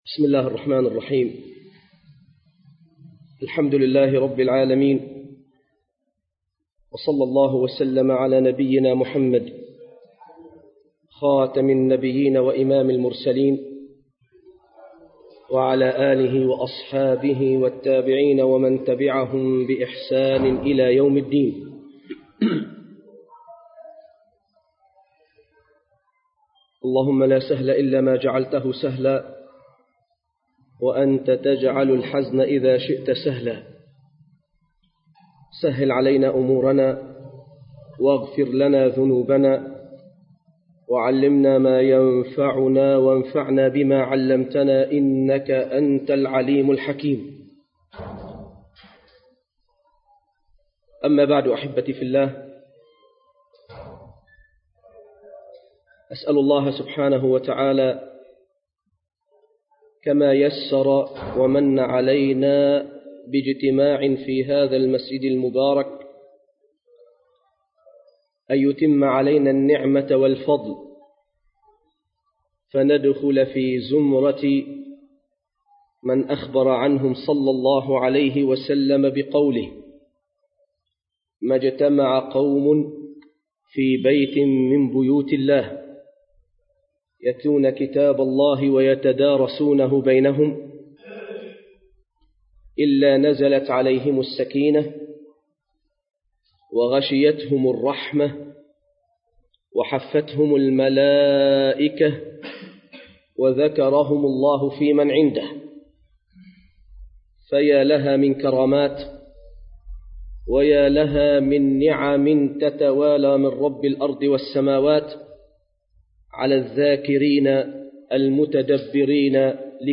الموضوع: أهمية تدبر القرآن المكان: مسجد القلمون الغربي